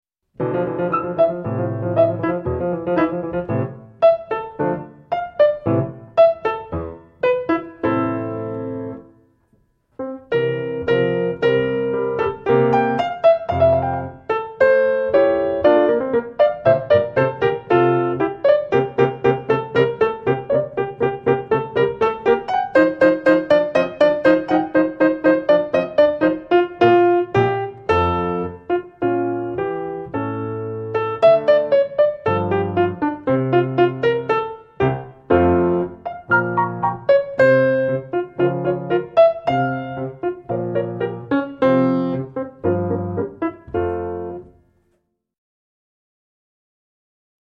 Le carte – Cantata